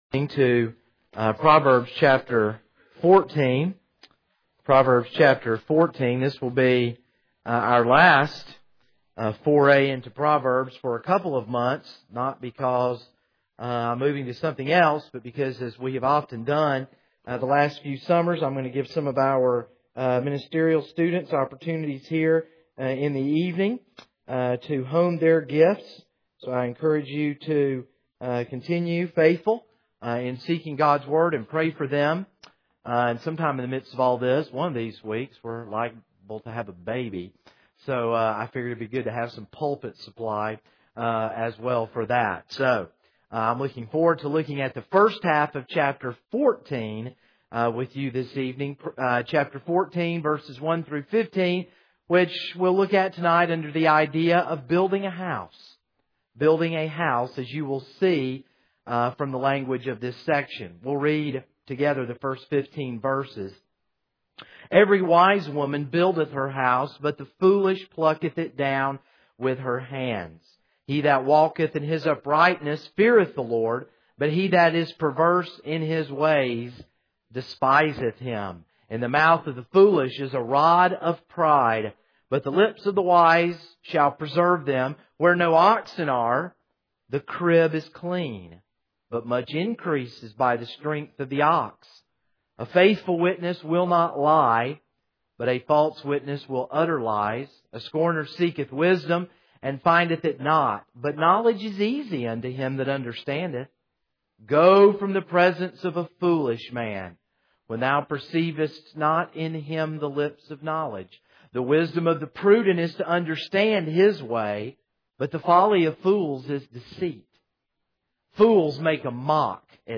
This is a sermon on Proverbs 14:1-15.